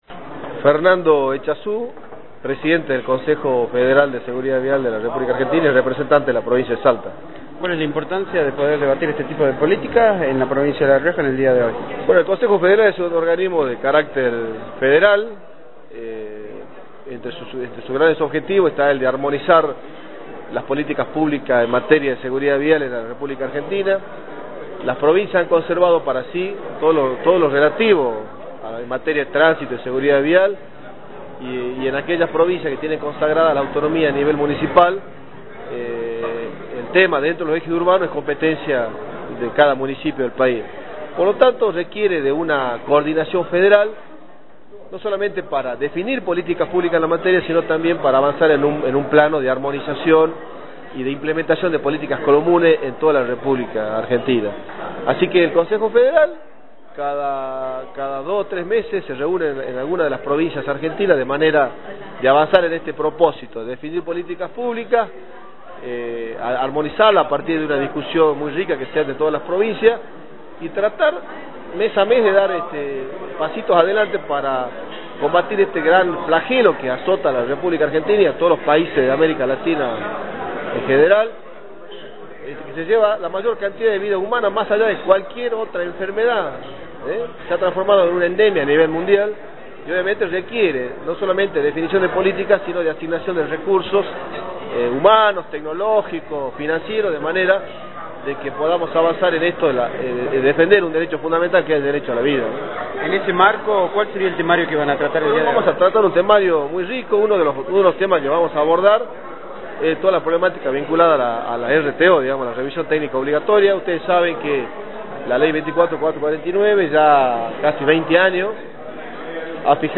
El gobernador de La Rioja, Luis Beder Herrera presidió el acto de apertura de la LXI Asamblea del Consejo Federal de Seguridad Vial.
Fernando Echazu, presidente del Consejo Federal de Seguridad Vial  Felipe Álvarez, ministro de Gobierno  Walter Flores, ministro de Educación  Teresita Madera, ministra de Desarrollo Social
fernando-echazu-presidente-del-consejo-federal-de-seguridad-vial.mp3